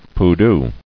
[pu·du]